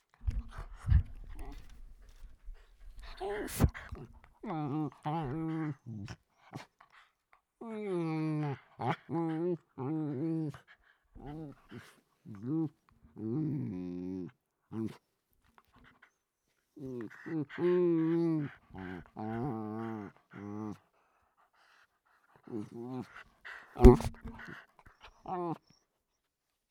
miniature-dachshunds-playing.wav